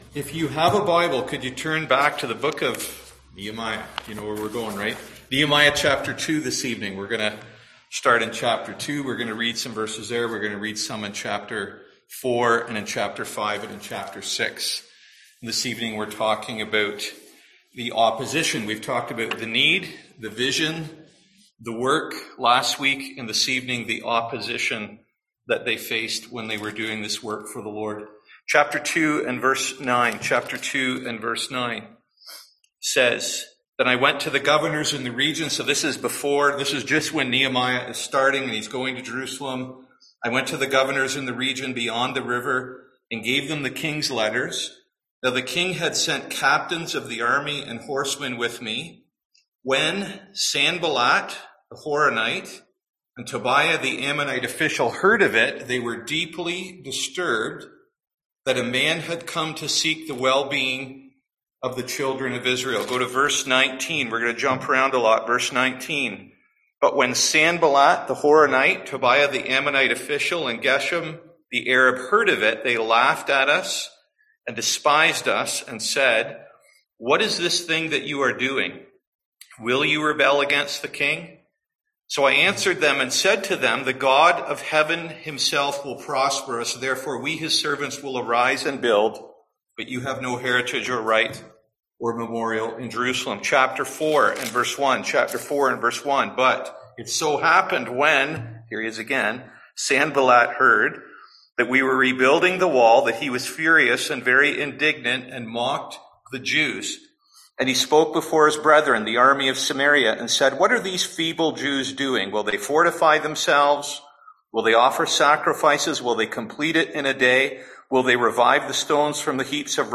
Nehemiah 2021 Passage: Nehemiah 4-6 Service Type: Seminar